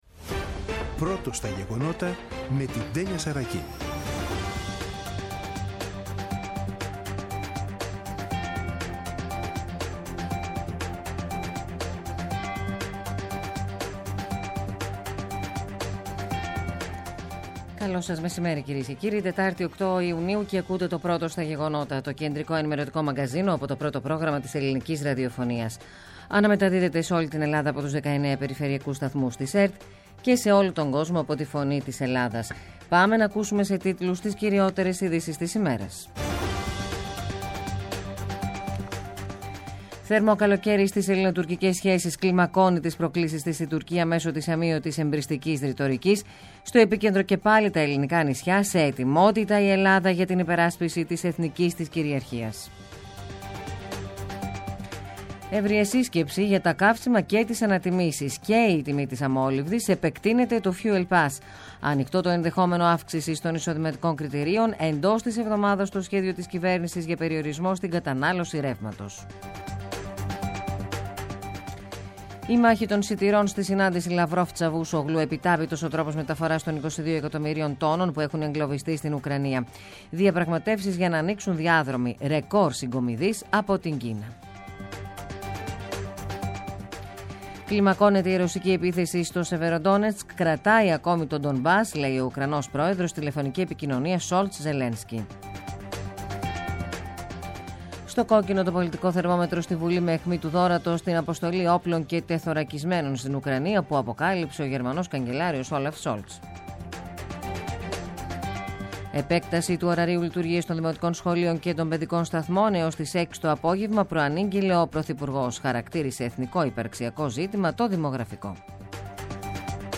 “Πρώτο στα γεγονότα”. Το κεντρικό ενημερωτικό μαγκαζίνο του Α΄ Προγράμματος , από Δευτέρα έως Παρασκευή στις 14.00. Με το μεγαλύτερο δίκτυο ανταποκριτών σε όλη τη χώρα, αναλυτικά ρεπορτάζ και συνεντεύξεις επικαιρότητας.